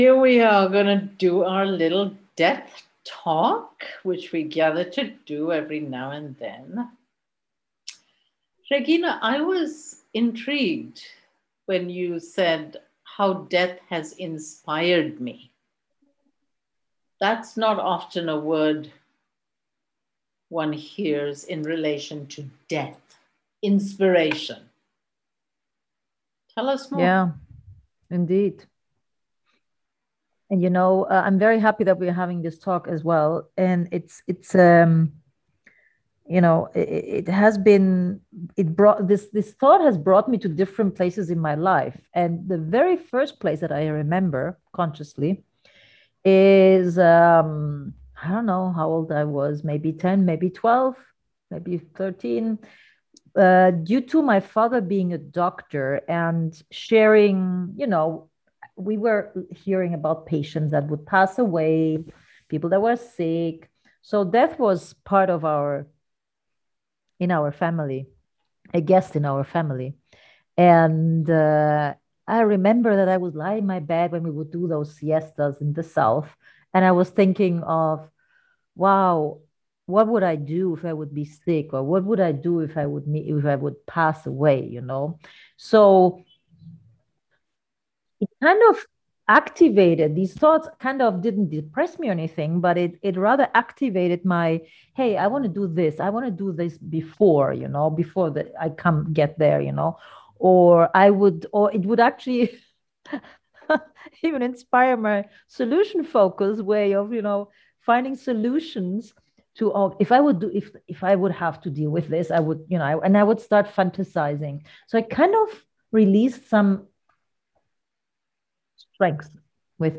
A 25 minutes kitchen table conversation